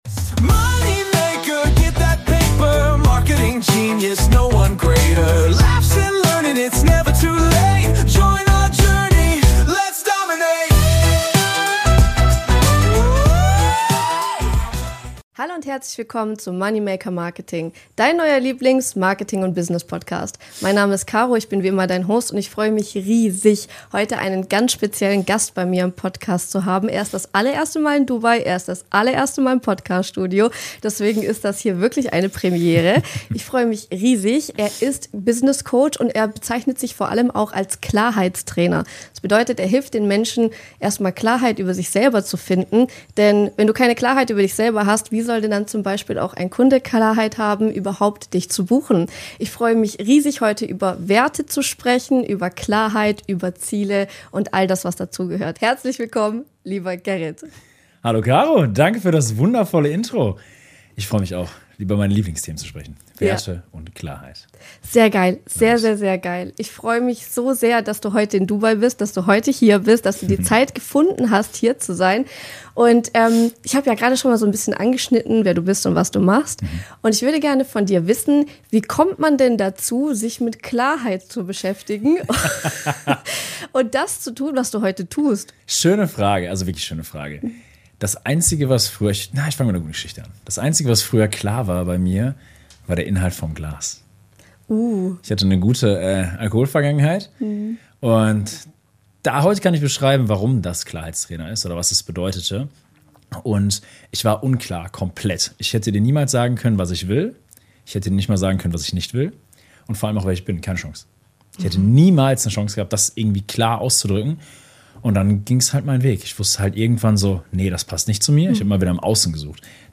Im Gespräch geht es um Wendepunkte, Identitätsveränderung, Wertearbeit und die Frage, warum viele High Performer zwar erfolgreich wirken, aber innerlich im Autopilot-Modus funktionieren.